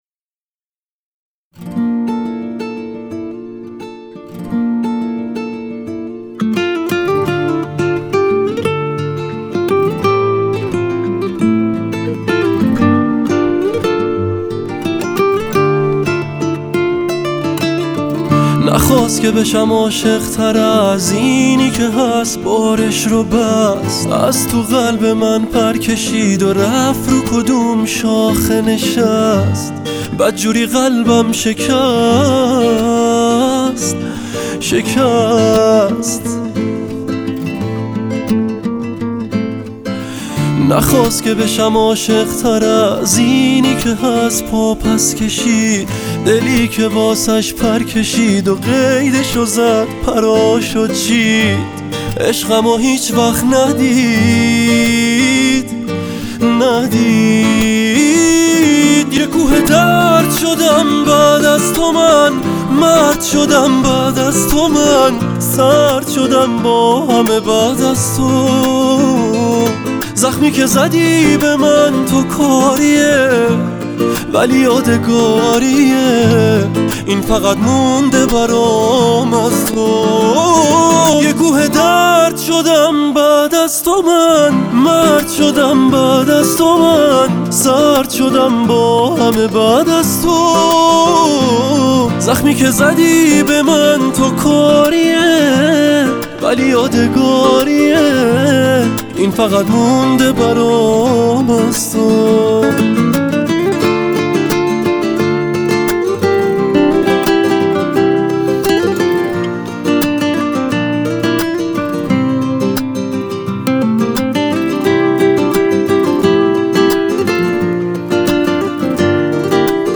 Guitar Version